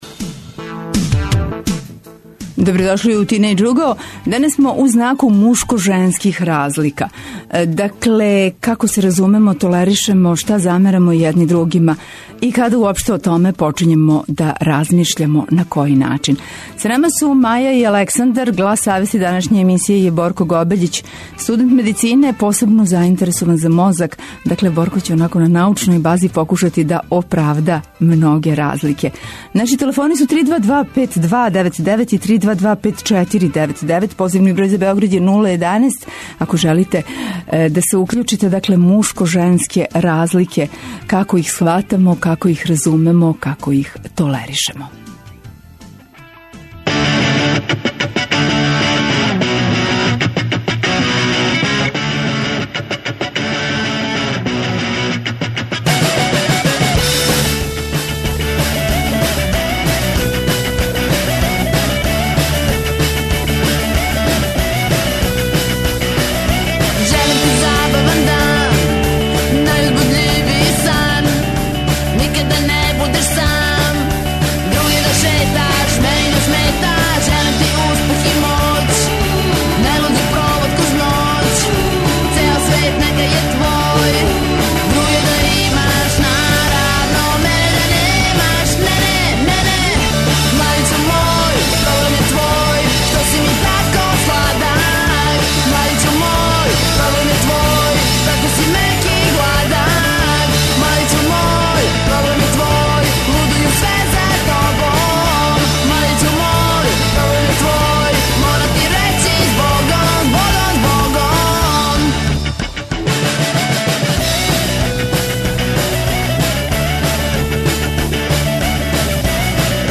Гости су нам тинејџери, који су и покренули ову тему.